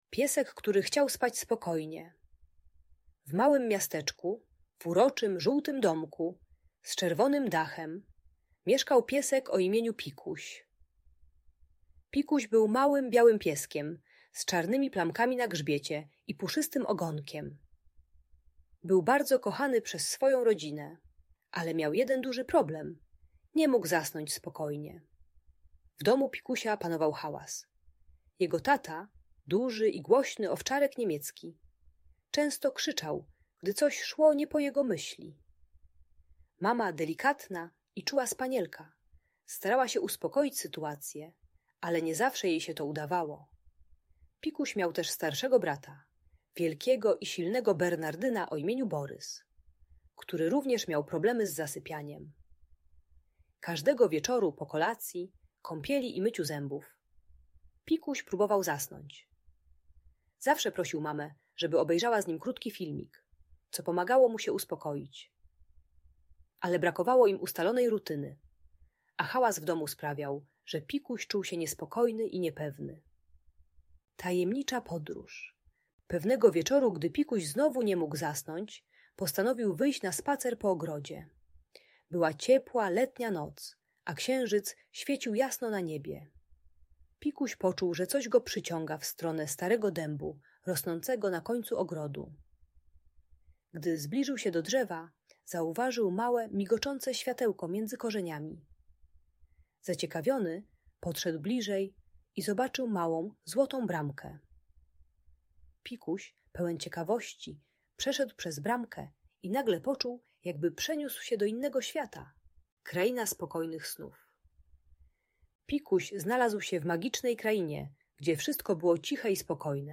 Ta bajka dla dziecka które nie może zasnąć uczy budowania wieczornej rutyny i techniki tworzenia spokojnego otoczenia przed snem. Idealna audiobajka usypiająca dla przedszkolaków.